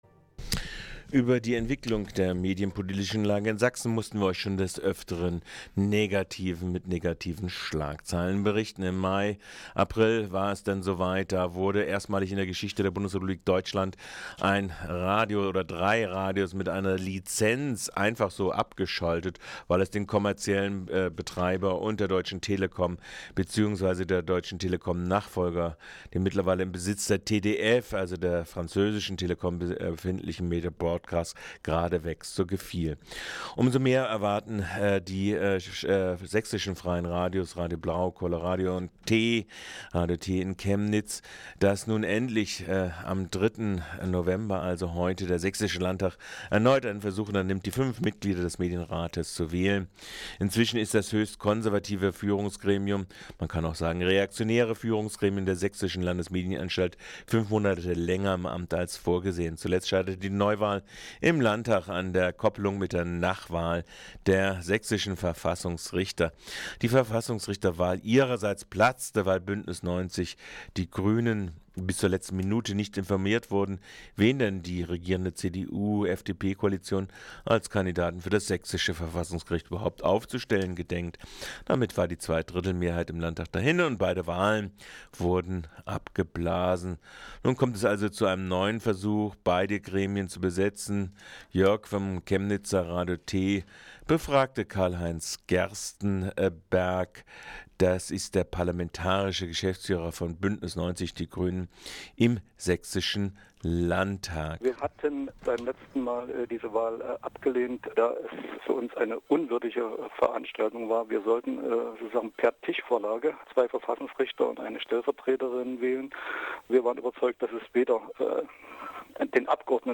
Interview mit Karl-Heinz Gerstenberger (MdL, B90/Grüne) zur Paketwahl von Verfassungsrichtern und Medienrat im Sächsischen Landtag.